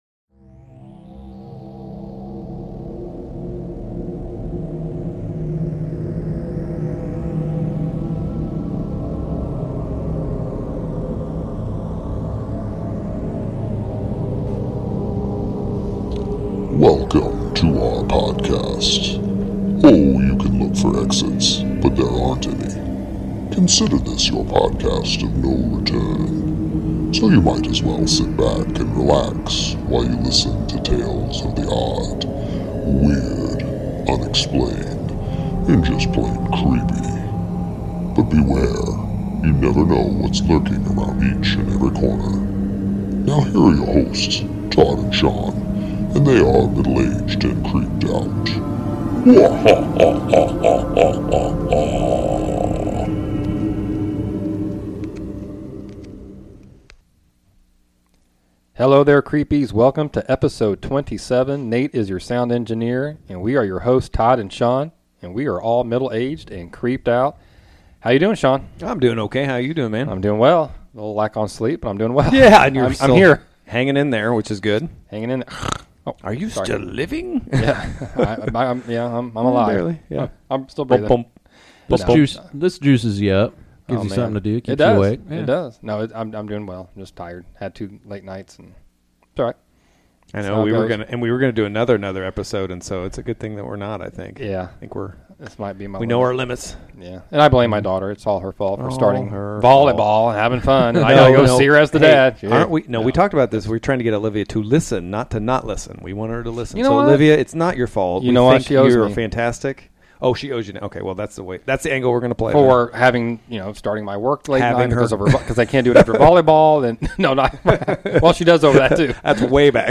Guest Chat